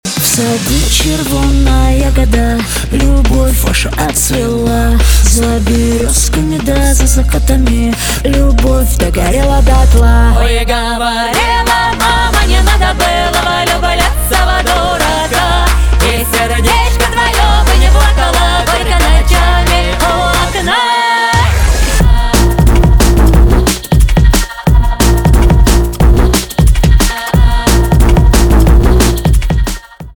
поп
грустные
битовые , басы